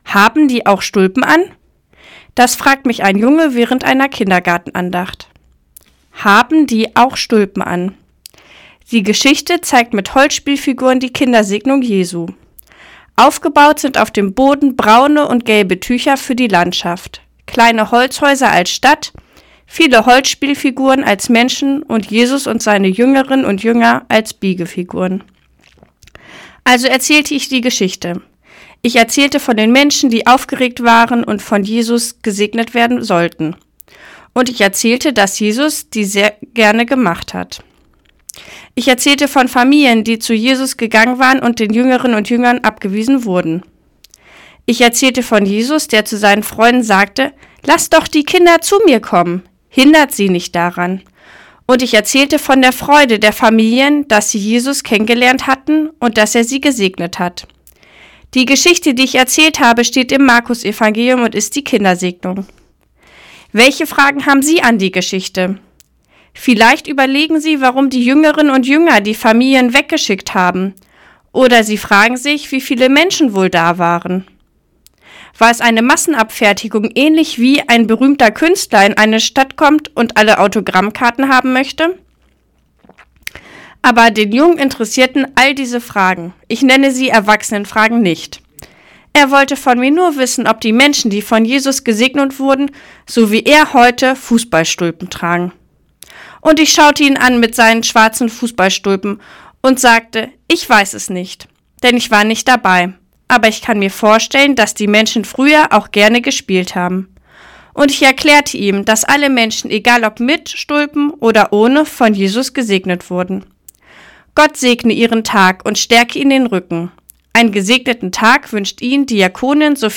Radioandacht vom 20. Oktober